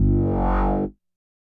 bass note01.wav